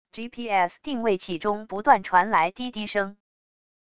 gps定位器中不断传来滴滴声wav音频